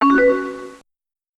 Added AIM sfx
imsend.ogg